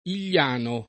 [ il’l’ # no ]